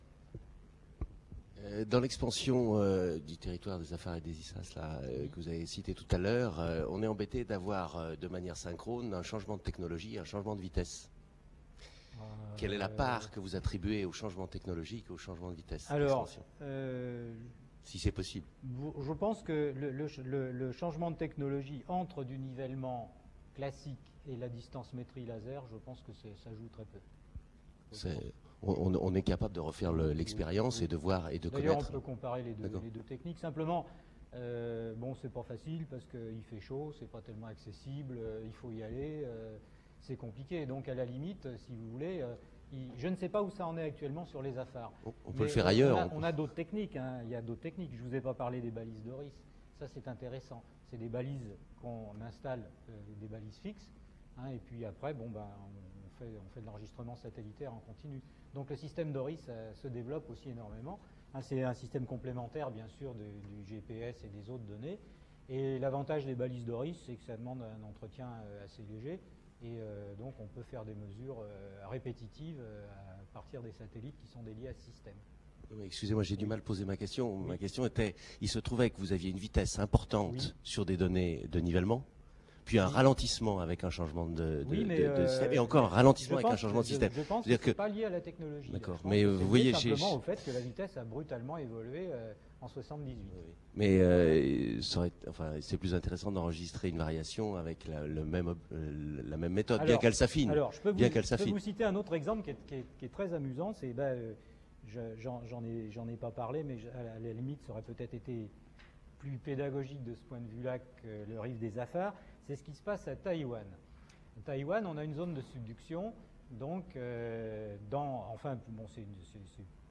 Réponses à quelques questions posées par le public à l’issue de la conférence.